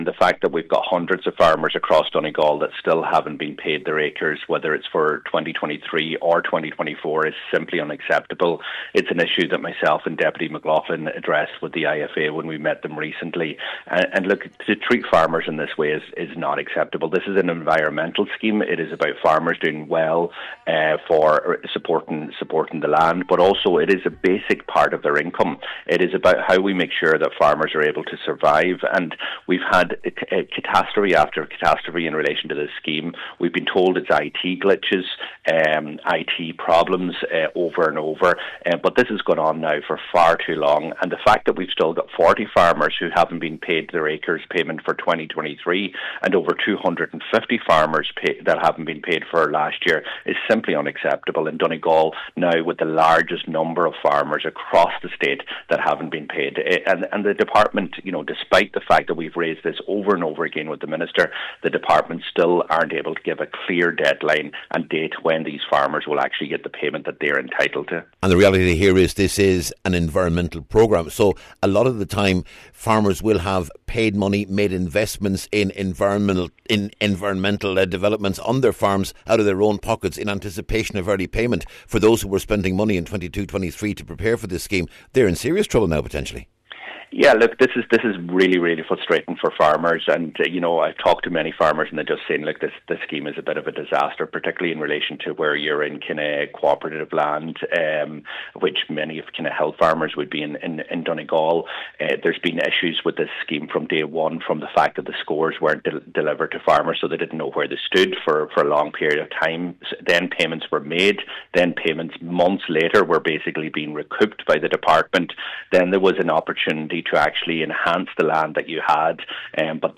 Deputy Doherty told Highland Radio News these delays are undermining that whole vision……………